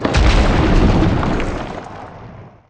wallbreak.ogg